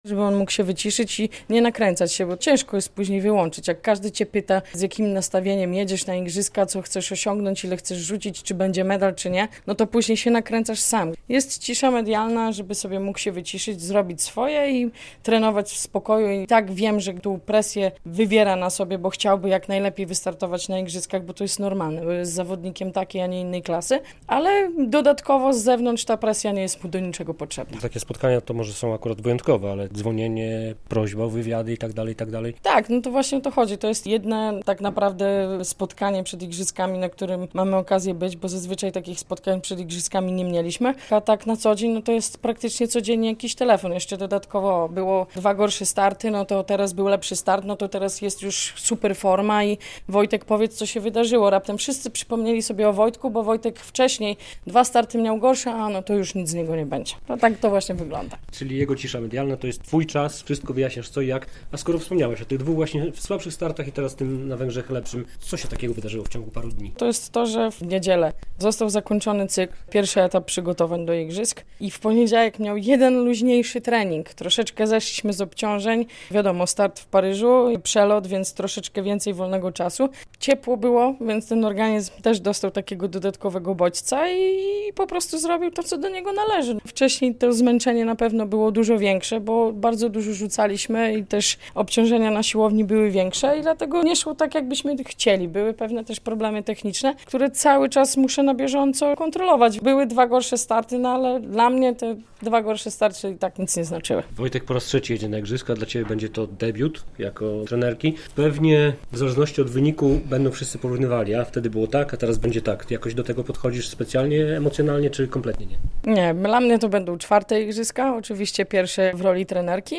Radio Białystok | Gość | Joanna Fiodorow - trenerka Wojciecha Nowickiego